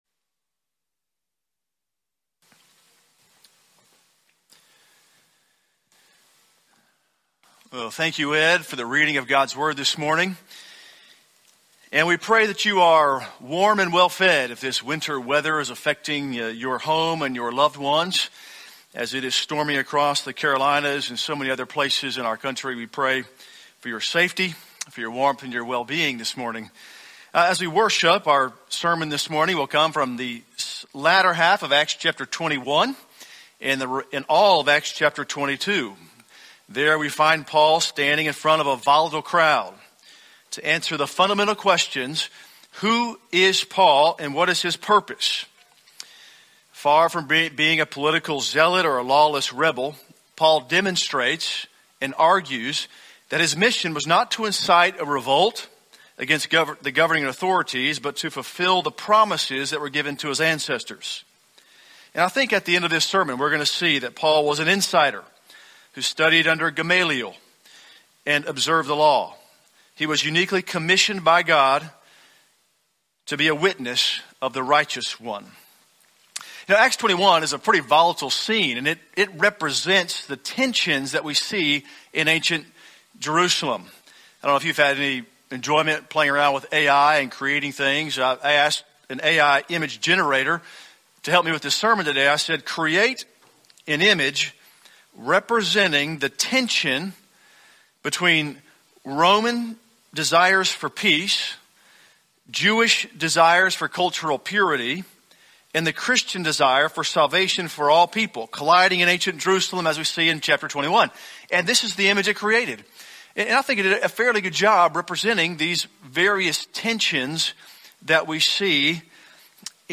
Sermon Archives
From Series: "English Sermons - 10:15"